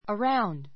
əráund